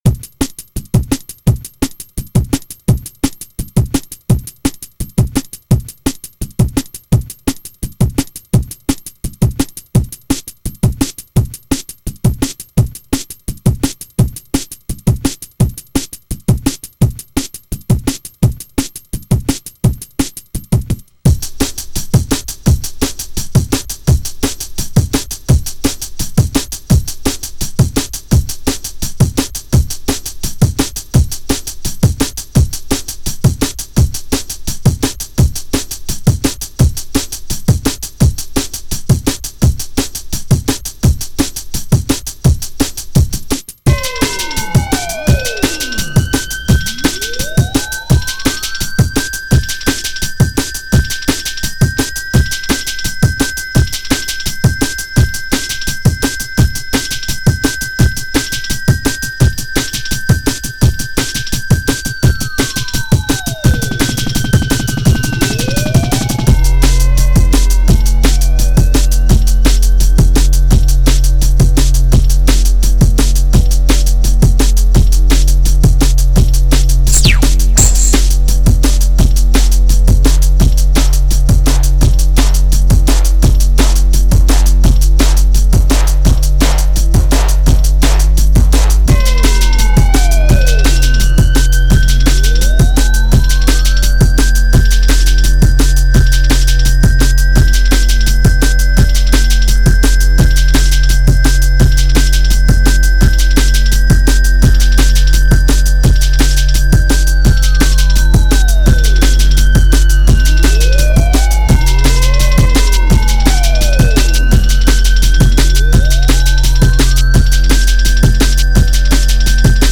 A little bit of drum, a little bit of bass